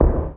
SZ KICK 15.wav